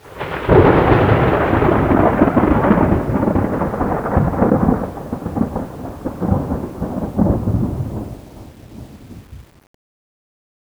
thunder1.wav